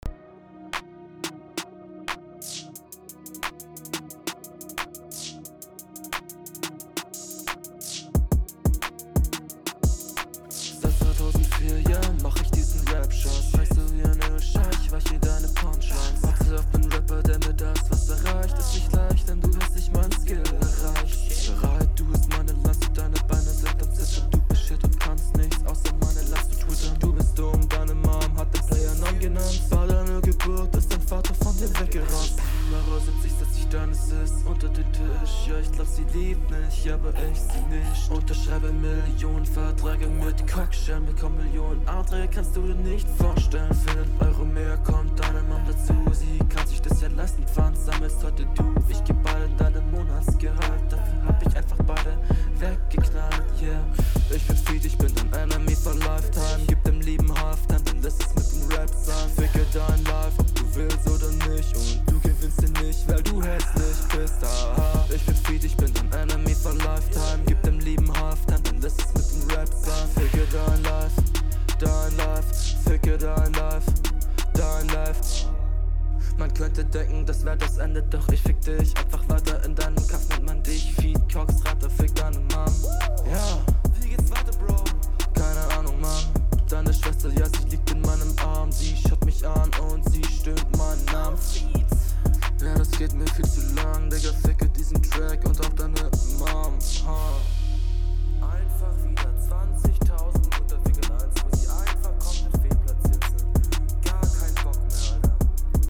Also Flow ist ausbaubar aber flüssig, man merkt das ist es Battle und YO Probier …
Du hast deine Stimme etwas zu leise abgemischt.